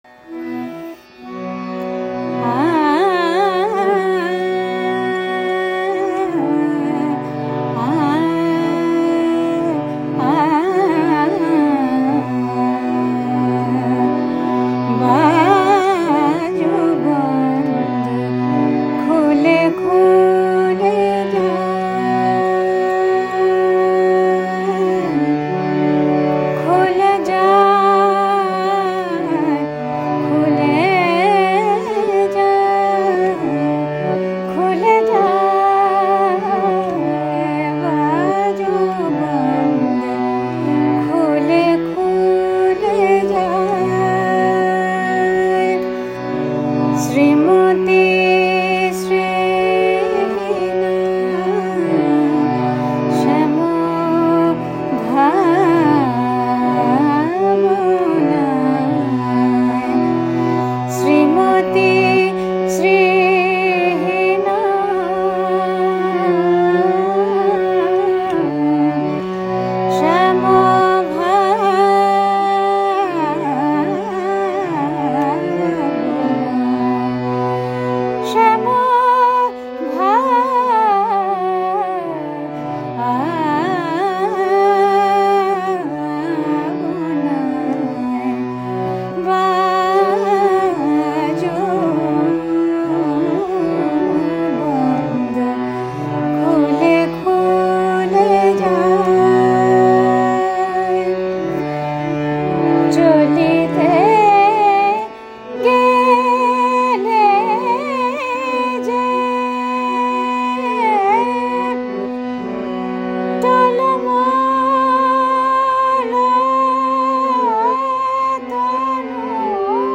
বিখ্যাত বাংলা ঠুংরি ‘বাজুবন্দ খুলে খুলে যায়’, যা আসলে ভৈরবী ঠুংরি ‘বাজুবন্দ খুল খুল যায়’-এর বাংলা রূপ